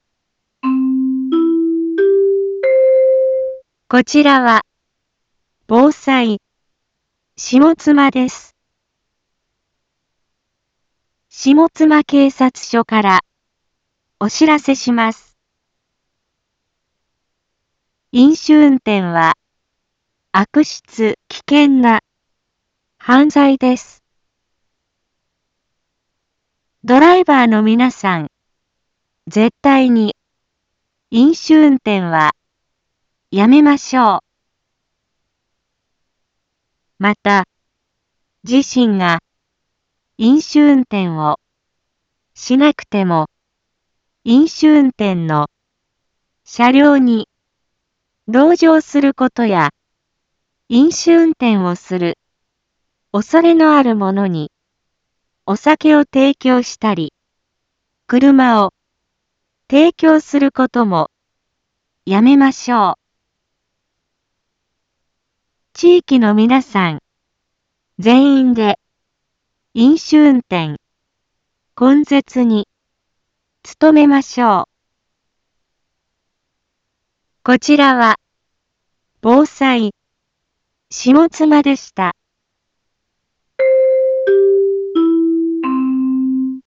Back Home 一般放送情報 音声放送 再生 一般放送情報 登録日時：2023-12-15 17:31:30 タイトル：飲酒運転根絶のための県下一斉広報日につい インフォメーション：こちらは、防災、下妻です。